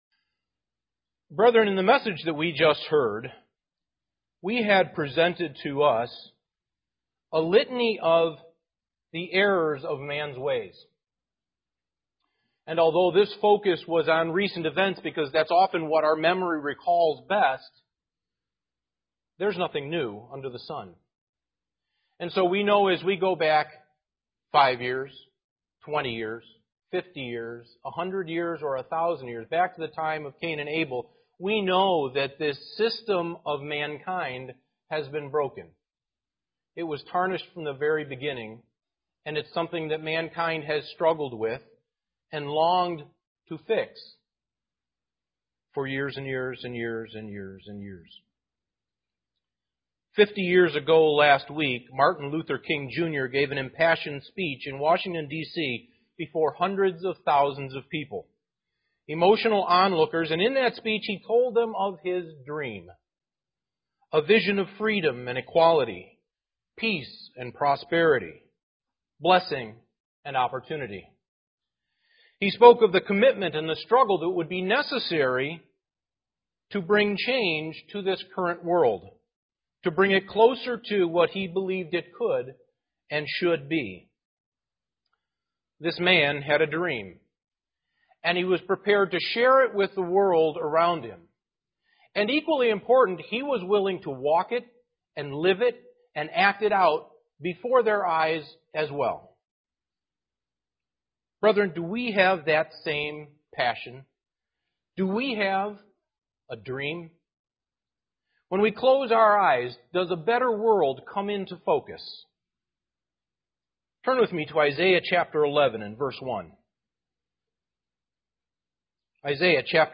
Print The Dream UCG Sermon Studying the bible?